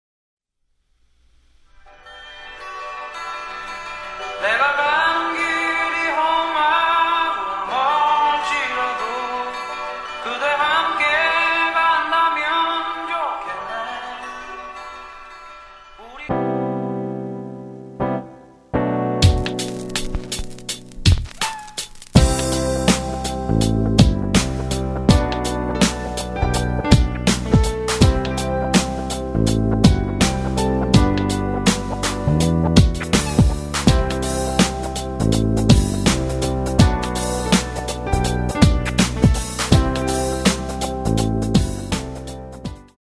키 G
원곡의 보컬 목소리를 MR에 약하게 넣어서 제작한 MR이며